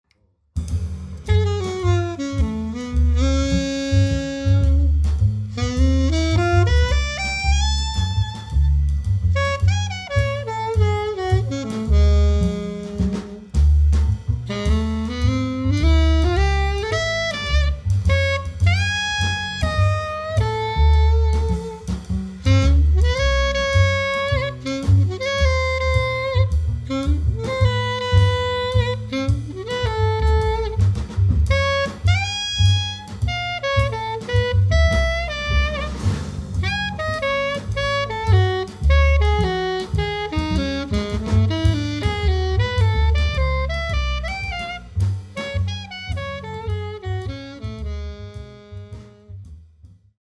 sax
bass
drums